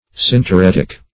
Search Result for " synteretic" : The Collaborative International Dictionary of English v.0.48: Synteretic \Syn`te*ret"ic\, a. [Gr.